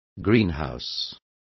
Complete with pronunciation of the translation of greenhouse.